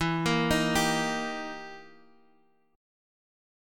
Eb/E chord
Eb-Major-E-x,x,2,3,4,3-8.m4a